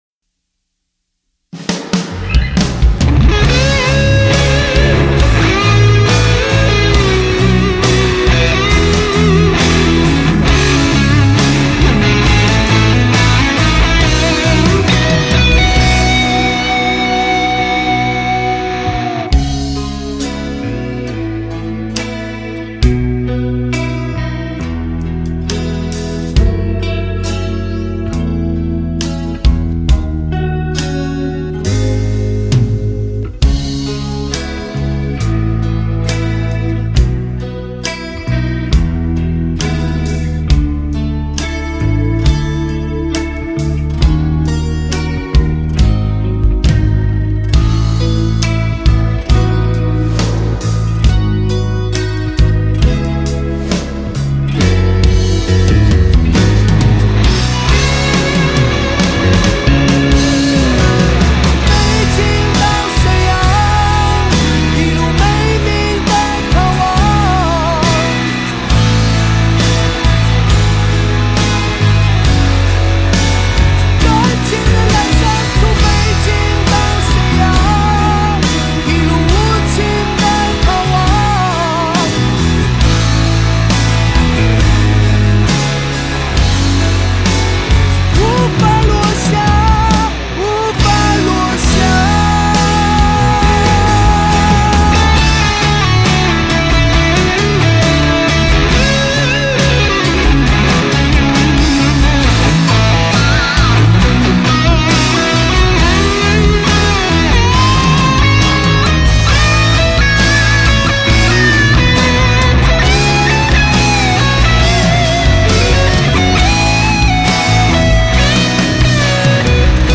唱片类型：华语流行